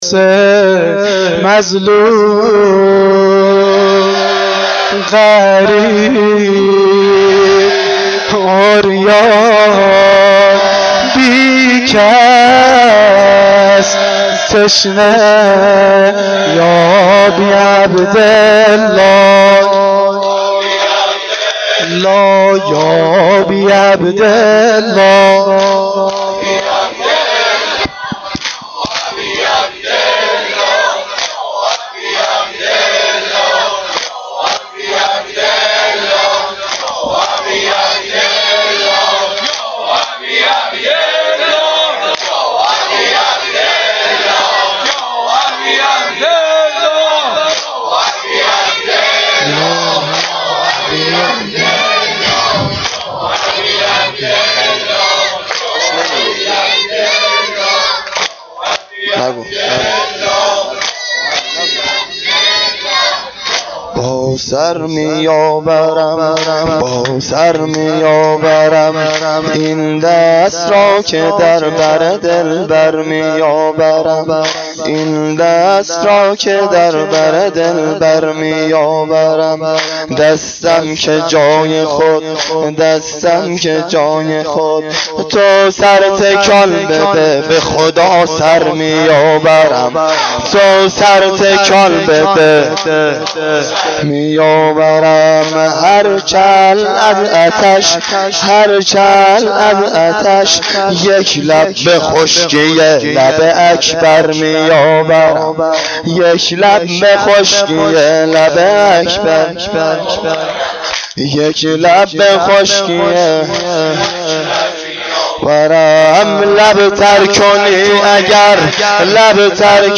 واحد شب هشتم محرم1393